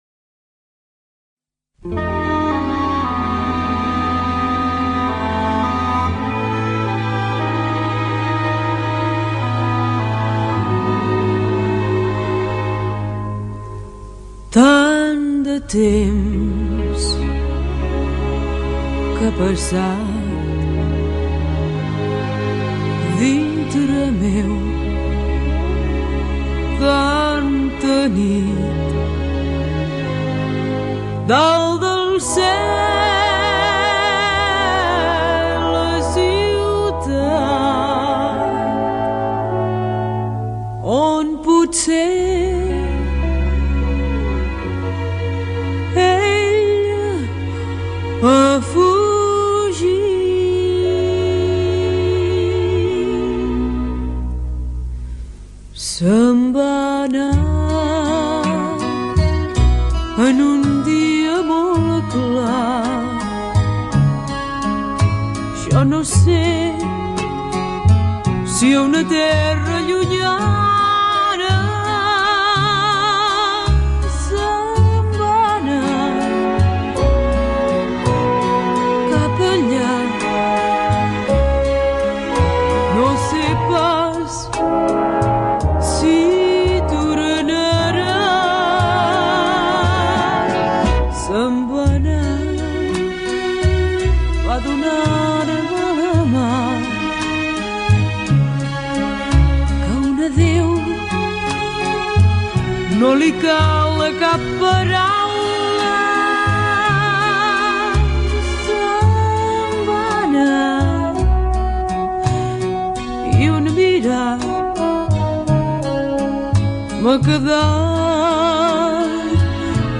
cridava una mica